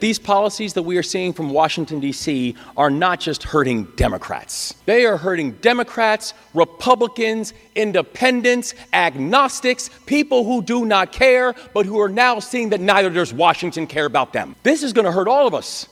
During his speech to the Maryland Association of Counties, Governor Wes Moore said that the steady leadership in the state is what will get Maryland through the issues it is facing internally and externally.